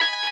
guitar_005.ogg